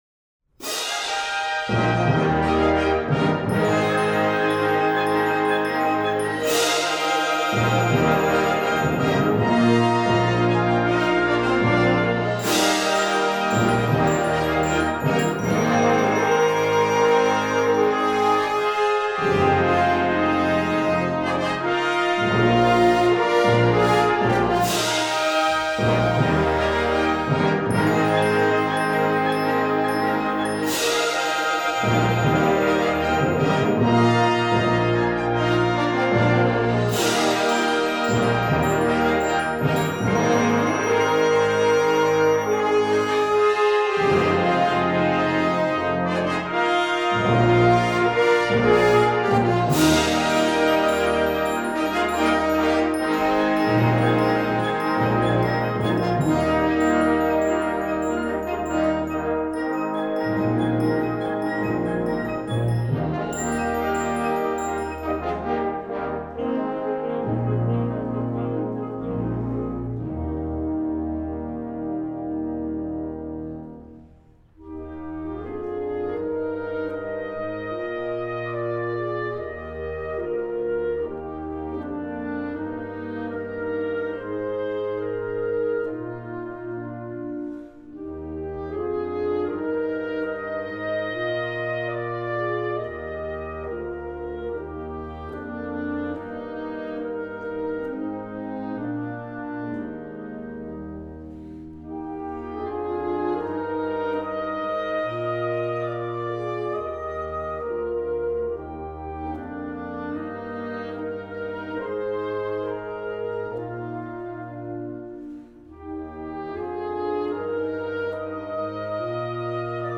Instrumentation: Blasorchester
Sparte: Sinfonische Musik